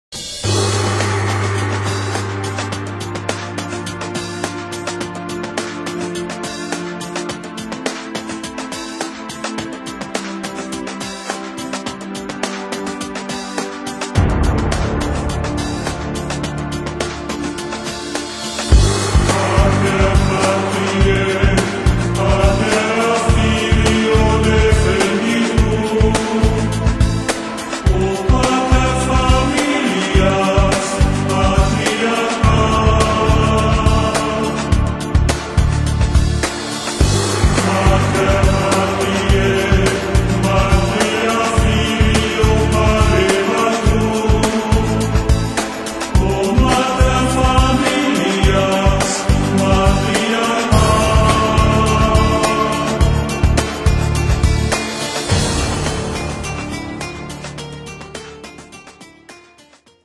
Идея соединения Григорианского пения и современной музыки